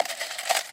Звуки скрепок
Звук освобождения скрепки из упаковки